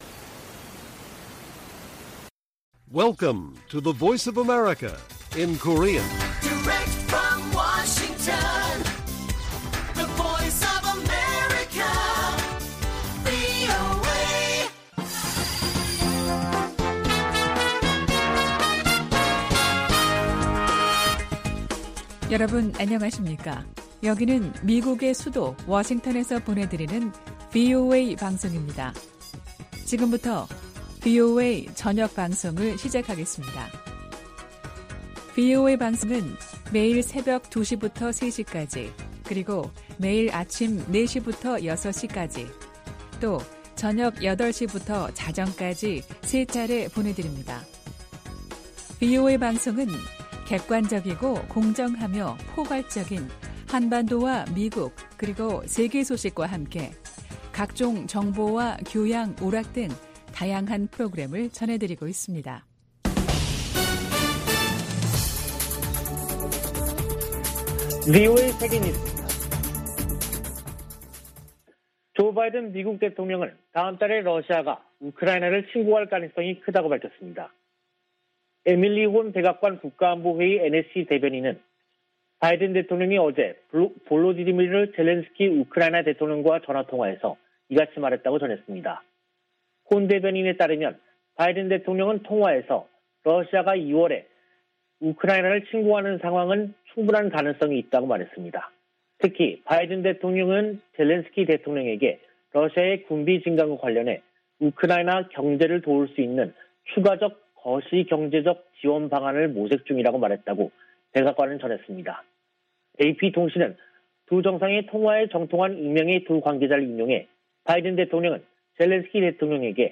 VOA 한국어 간판 뉴스 프로그램 '뉴스 투데이', 2022년 1월 28일 1부 방송입니다. 북한이 27일의 지대지 전술유도탄 시험발사와 지난 25일의 장거리 순항미사일 시험발사에 각각 성공했다고 28일 공개했습니다. 미 국무부는 외교 우선 대북 접근법을 확인하면서도, 도발하면 대가를 치르게 하겠다는 의지를 분명히 했습니다. 미 국방부는 북한의 불안정한 행동을 주목하고 있다며 잇따른 미사일 발사를 '공격'으로 규정했습니다.